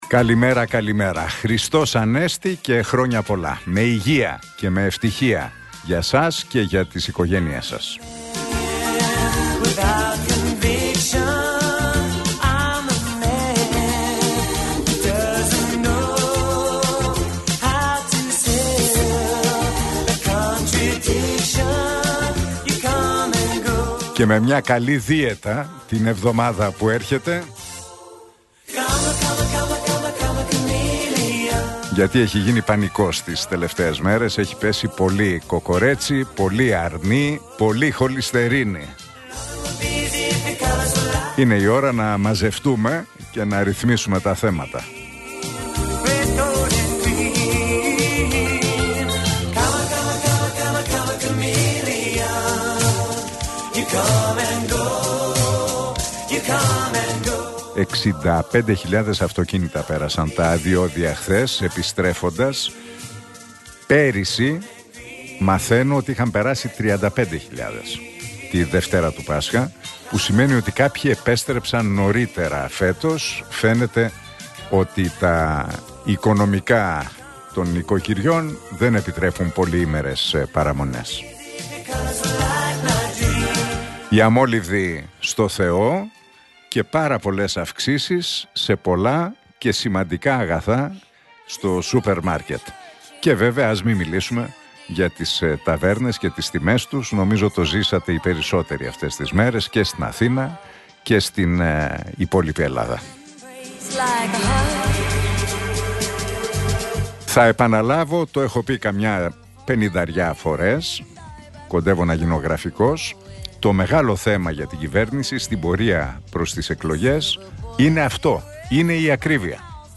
Ακούστε το σχόλιο του Νίκου Χατζηνικολάου στον ραδιοφωνικό σταθμό Realfm 97,8, την Τρίτη 14 Απριλίου 2026.